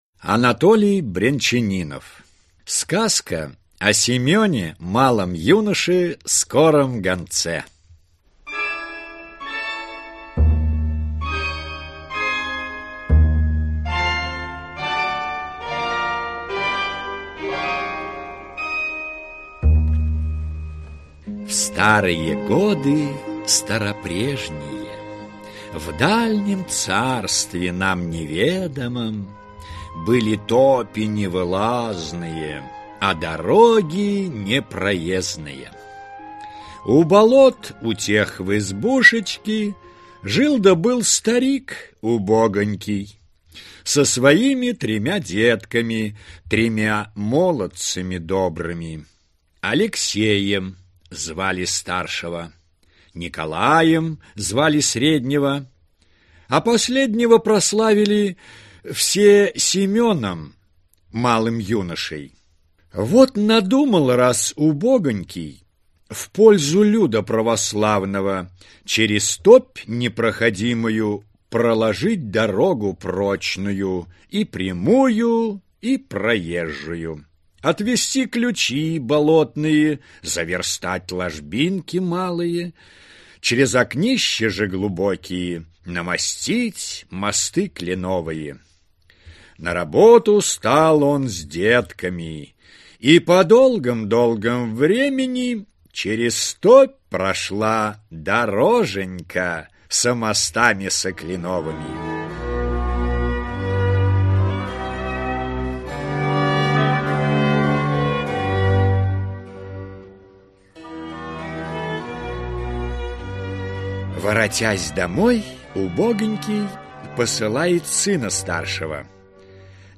Аудиокнига Русские народные сказки | Библиотека аудиокниг